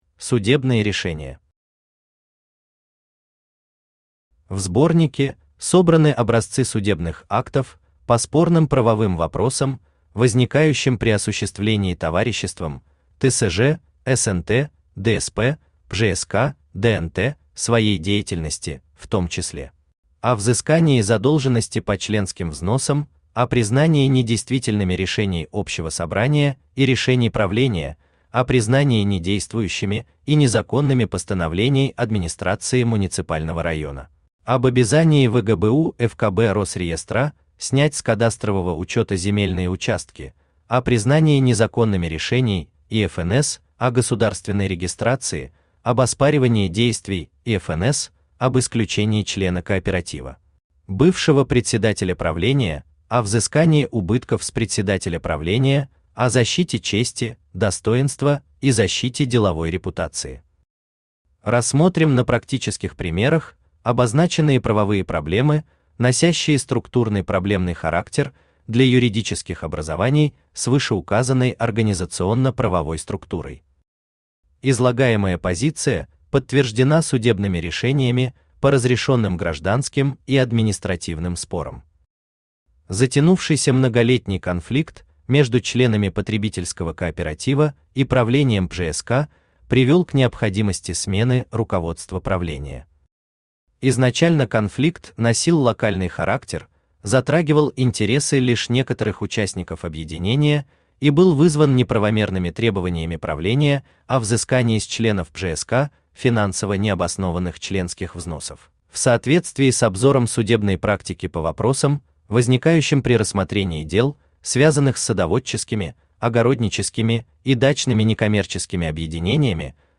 Аудиокнига История одного кооператива устами Фемиды | Библиотека аудиокниг
Aудиокнига История одного кооператива устами Фемиды Автор Роман Колганов Читает аудиокнигу Авточтец ЛитРес.